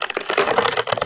ALIEN_Communication_22_mono.wav